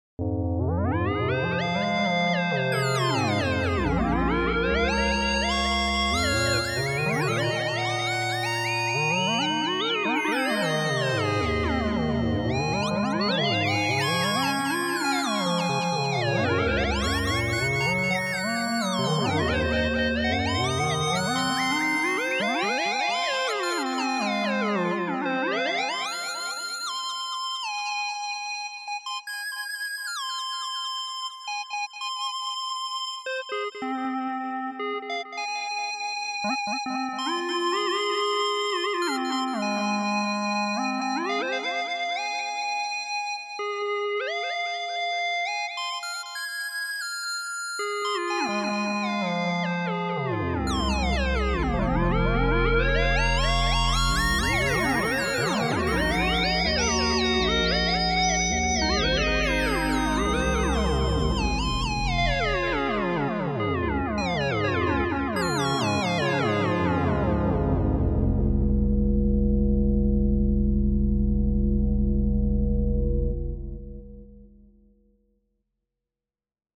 Since the music is in stereo, listening with high quality headphones or through a good home stereo system is advised.
Polyphony of up to five sounds at once can be achieved.
Each of these audio examples were improvised live, and were accompanied by a nice light show!